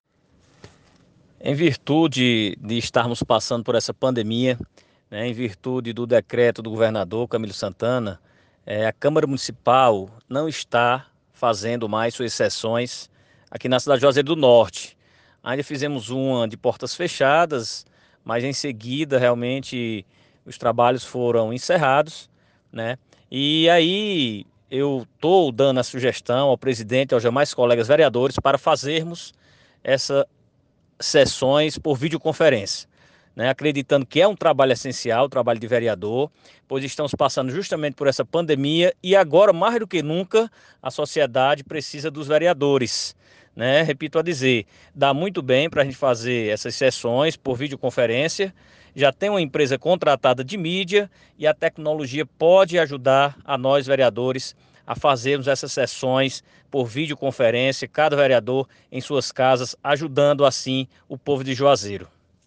Agra conversou com a reportagem do site Miséria e ele revela o que motivo de mobilizar os demais vereadores.